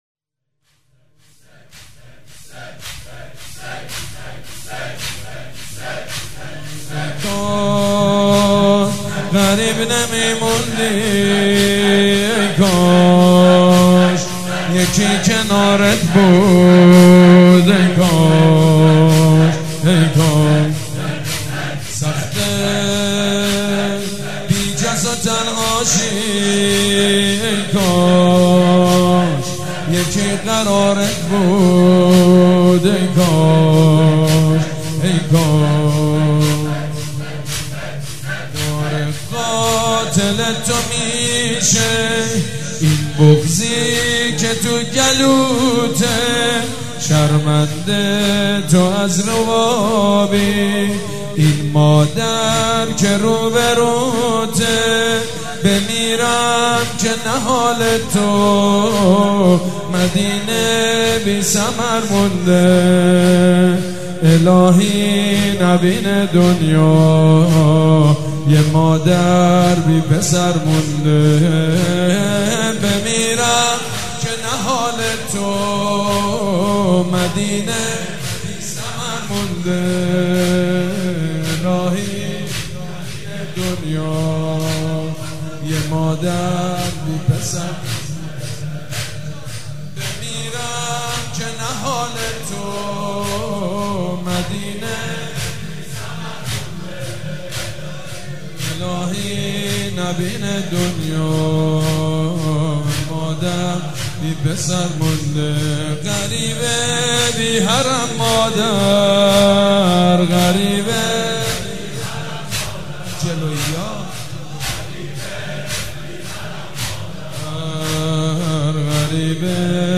مراسم شهادت حضرت ام البنین سلام الله علیها
حاج سید مجید بنی فاطمه